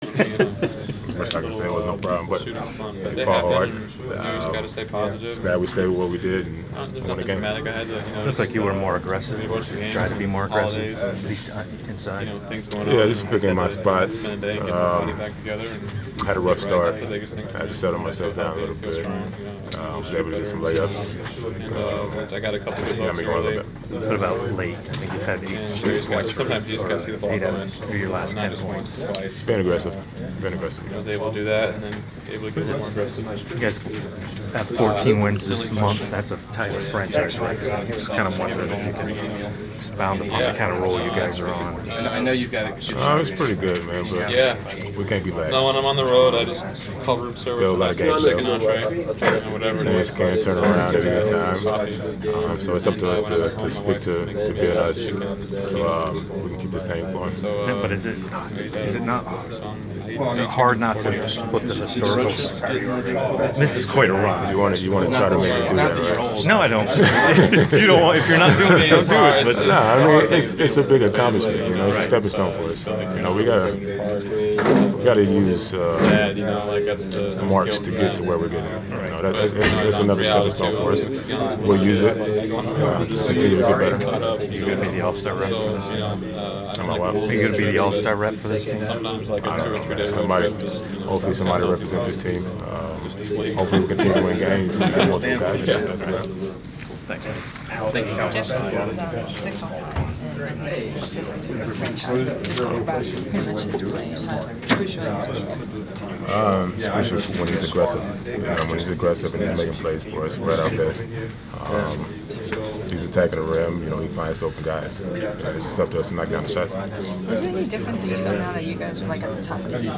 Inside the Inquirer: Postgame presser with Atlanta Hawks’ Paul Millsap (12/20/14)
We attended the postgame presser of Atlanta Hawks’ forward Paul Millsap following his team’s 109-101 home victory over the Cleveland Cavaliers on Dec. 30. He had a team-high 26 points and nine rebounds.